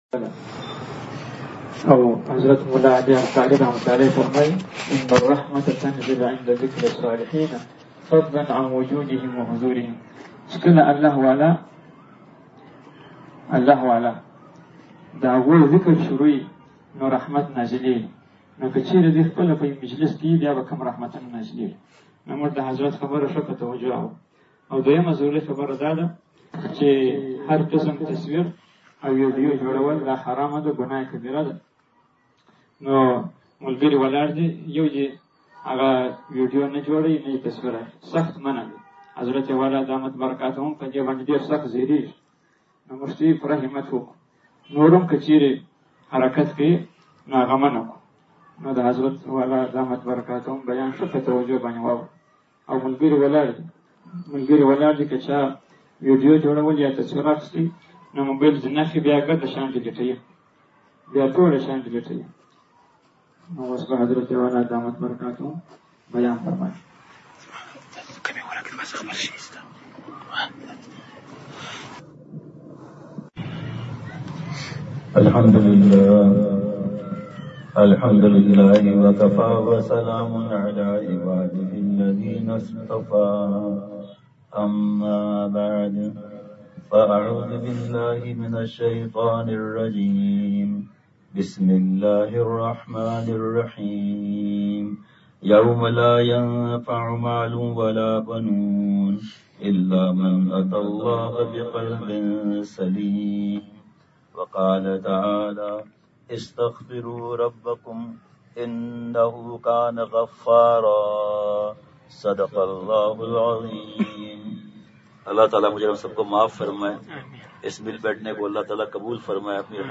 بمقام:مصطفی مسجد چمن بعد مغرب بیان۔
لہذا بعد مغرب مسجد میں بیان ہوا۔